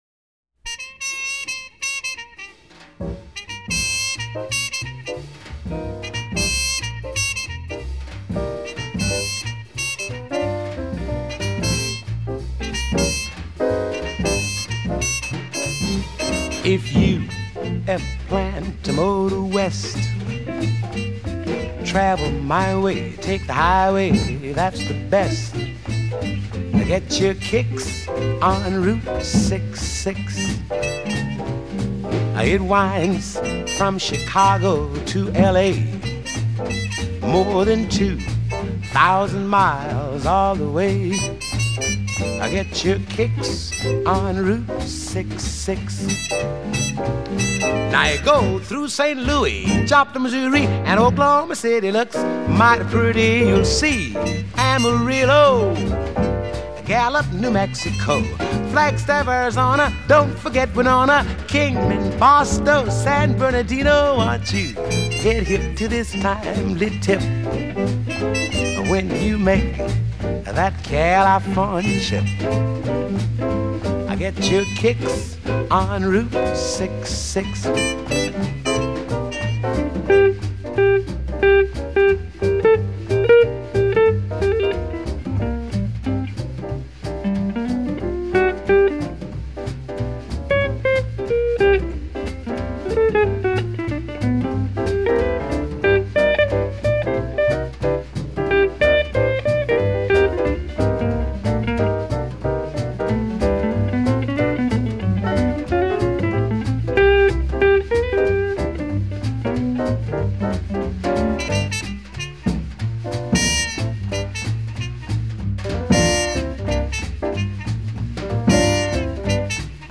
classic jazz performance
trumpet